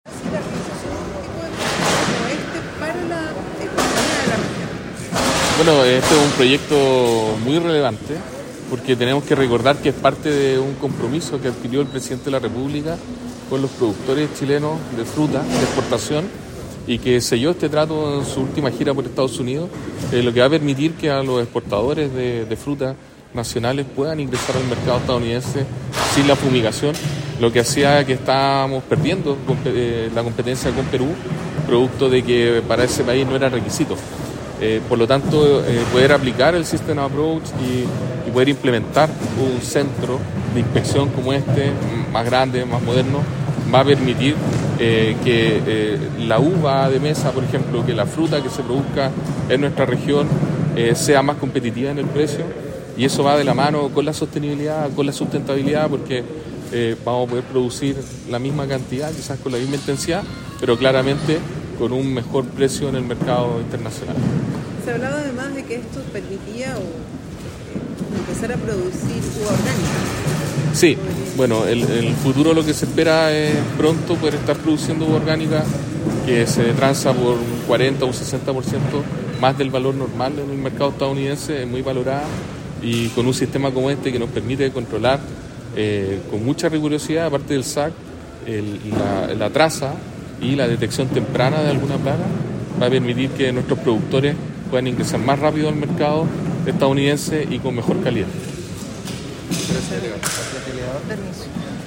EXPORTACIONES-SAG-Delegado-Presidencial-Regional-Galo-Luna-Penna.mp3